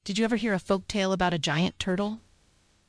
In fact, in some cases, the tone is completely off (for example, my voice).